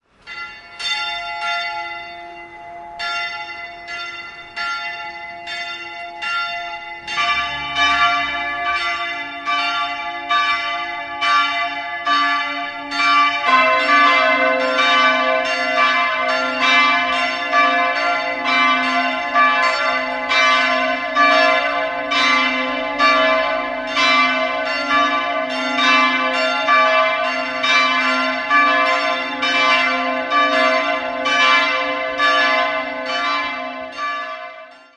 Die drei Läuteglocken sind Teil des 12-stimmigen Glockenspiels (Gesamttonfolge: c''-d''-e''-f''-g''-a''-b''-h''-c'''-d'''-e'''-f''').
Die Glocken c'' und f'' erklingen in Dur-Rippe.